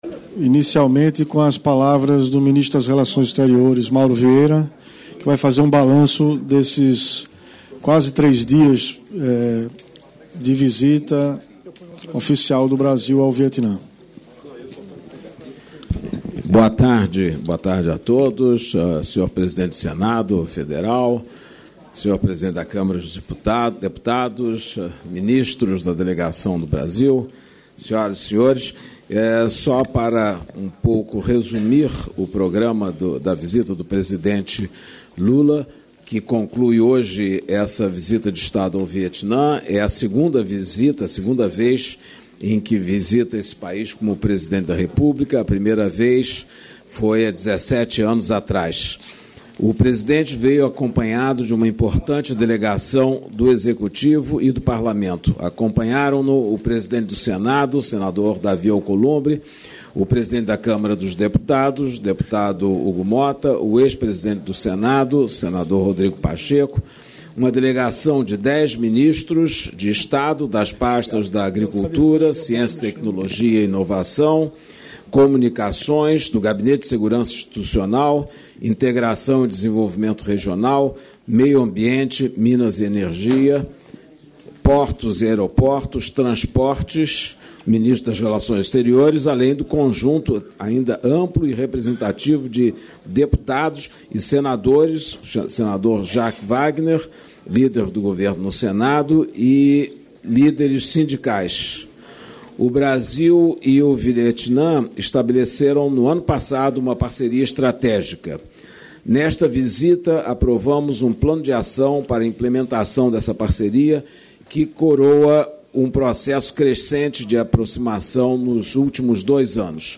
Íntegra dos discursos do presidente da República em exercício, Geraldo Alckmin, e do ministro da Saúde, Alexandre Padilha, na cerimônia de entrega de 156 ambulâncias para expansão e ampliação do Serviço de Atendimento Móvel de Urgência (Samu-192), nesta quinta-feira (27), no Canteiro Central da Esplanada dos Ministérios, em Brasília (DF).